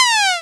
fall2.wav